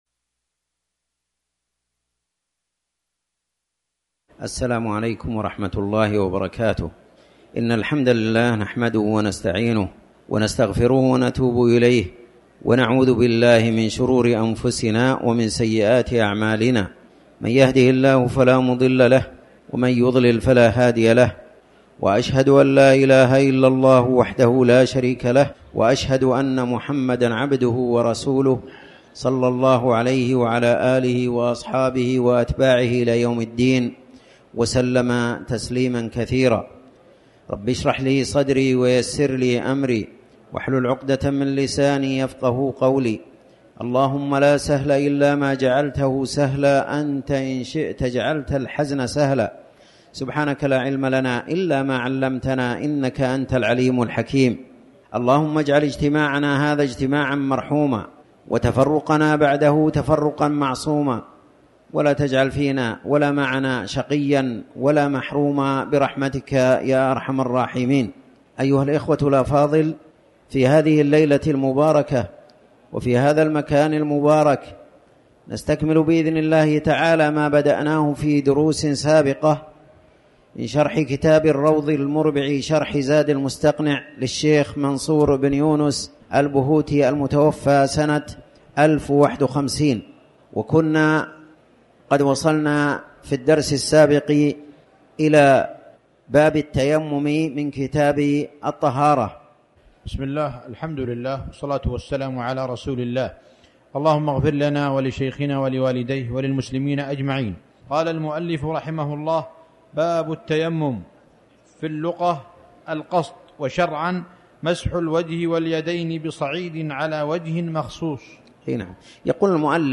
تاريخ النشر ٨ جمادى الأولى ١٤٤٠ هـ المكان: المسجد الحرام الشيخ